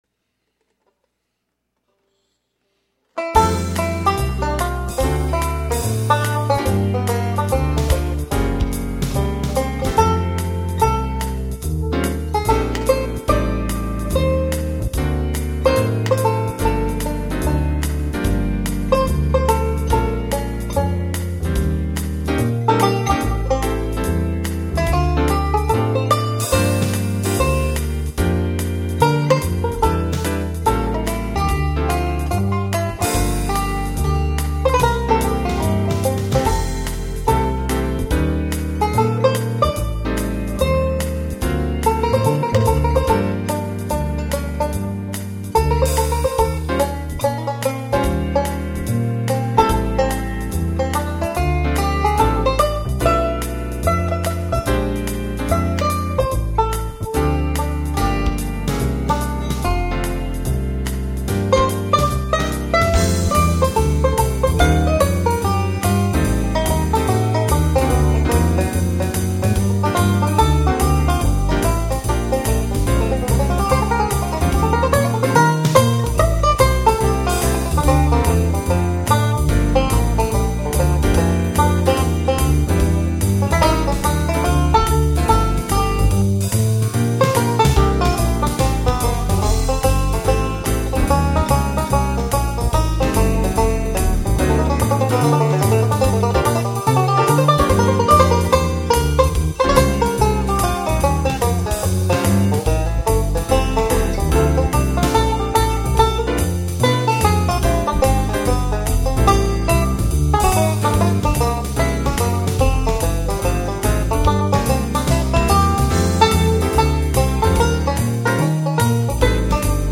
Bebop sax lines on a banjo.